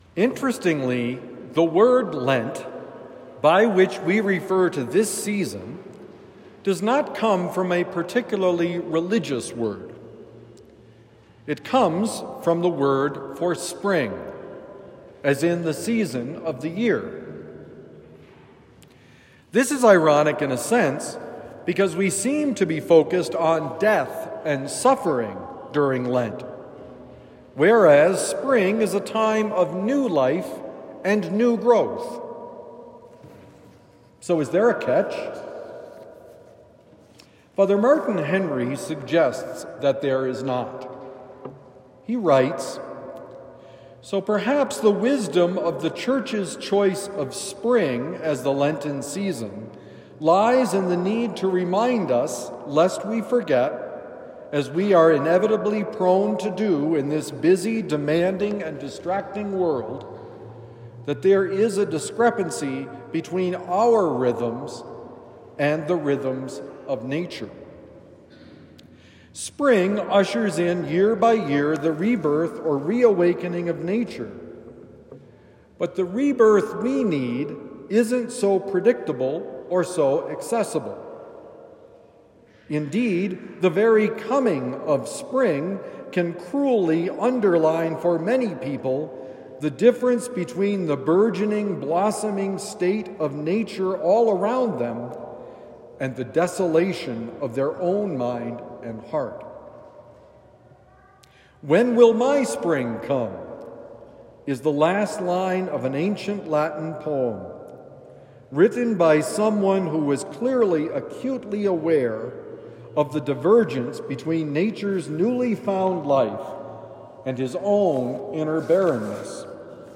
I believe. Help my unbelief. Homily for Sunday, March 16, 2025